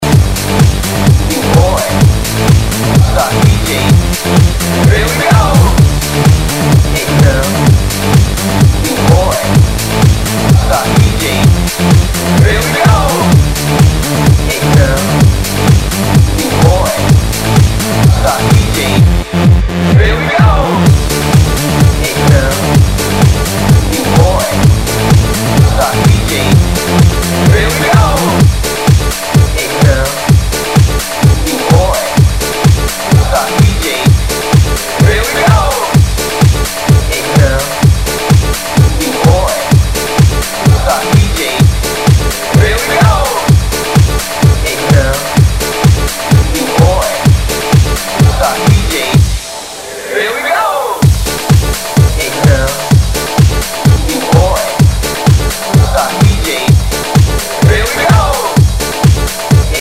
HOUSE/TECHNO/ELECTRO
類別 House